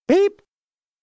colonel BEEP
beep-declicked.mp3